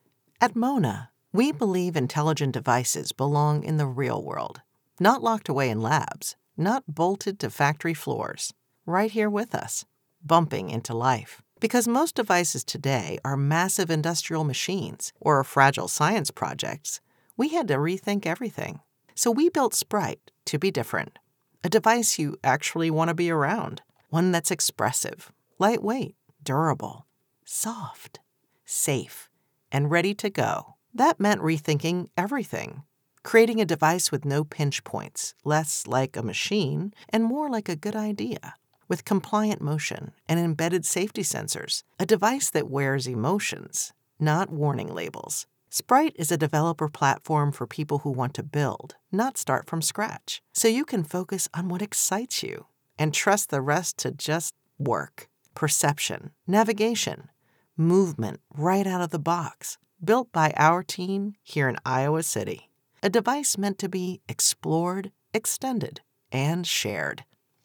Experienced Voiceover Pro
Corporate read
Middle Aged